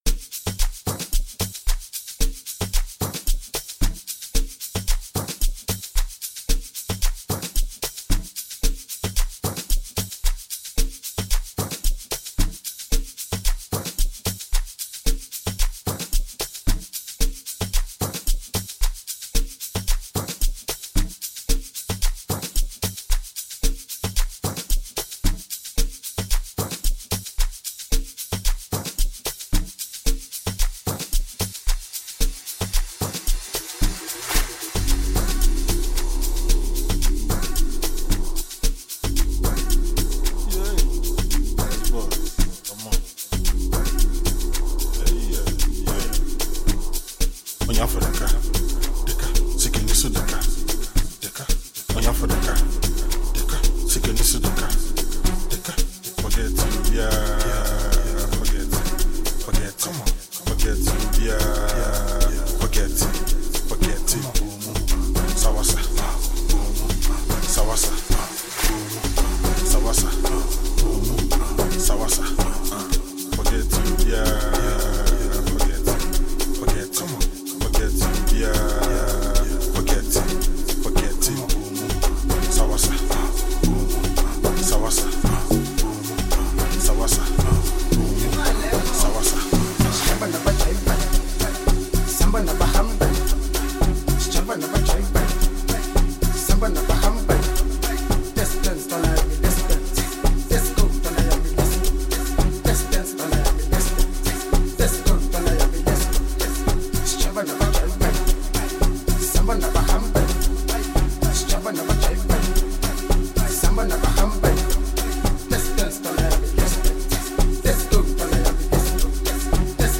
Ghana MusicMusic
Award-winning Ghanaian rapper